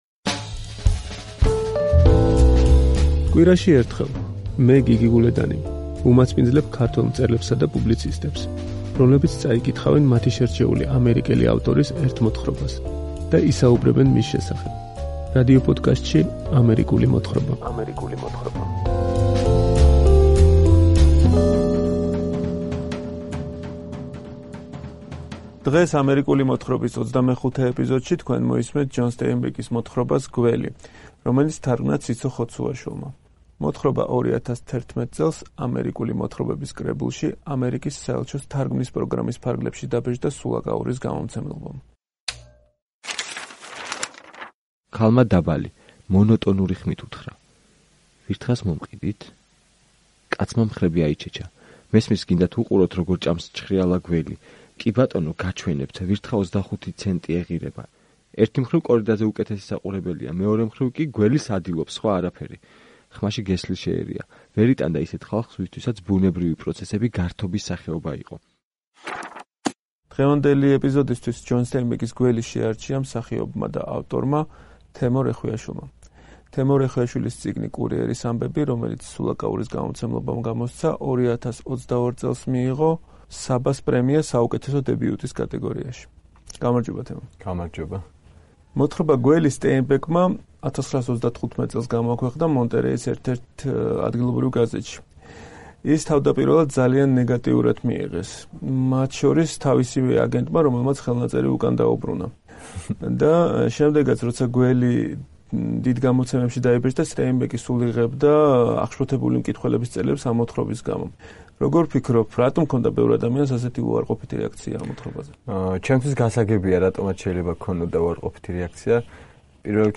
წაიკითხა მსახიობმა